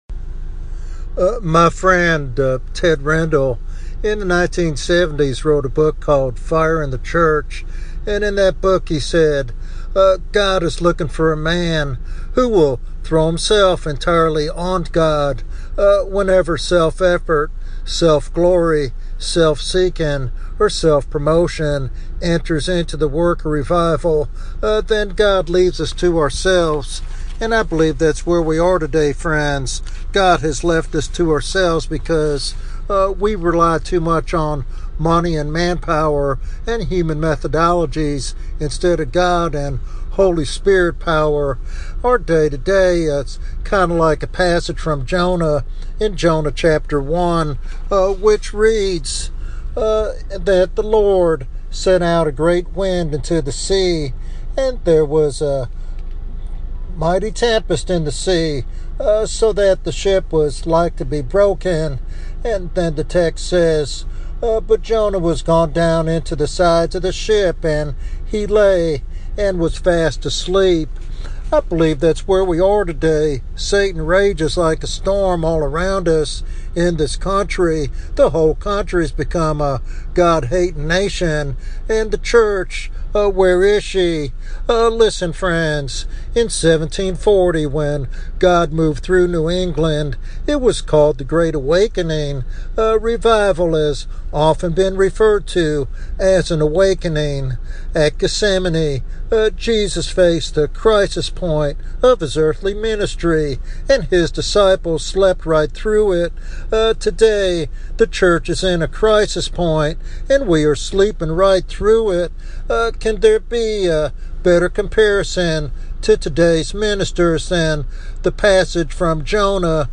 This sermon is a passionate plea for revival and a return to authentic, Spirit-empowered ministry.
Sermon Outline